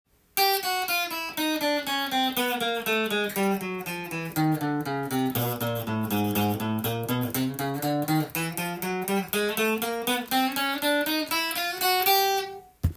クロマチックスケールとは、半音階でひたすらつながっているスケールです。
３フレットから